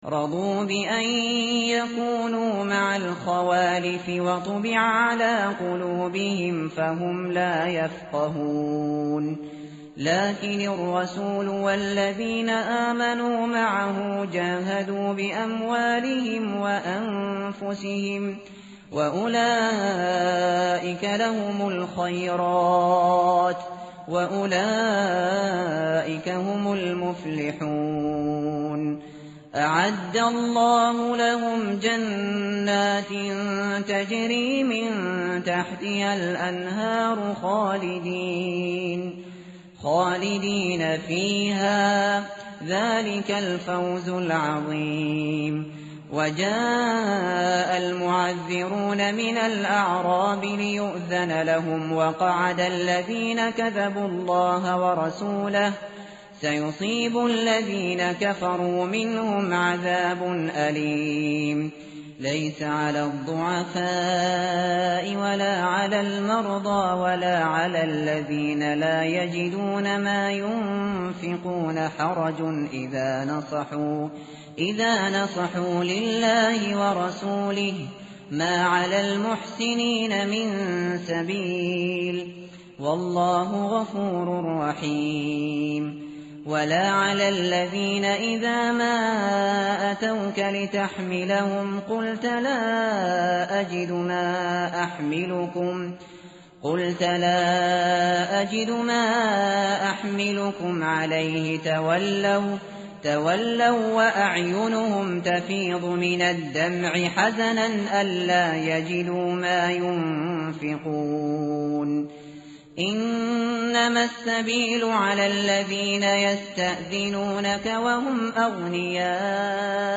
متن قرآن همراه باتلاوت قرآن و ترجمه
tartil_shateri_page_201.mp3